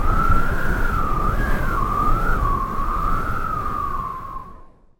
Howling Wind
Strong wind howling through structures with gusting surges and eerie tonal whistling
howling-wind.mp3